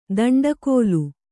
♪ daṇḍa kōlu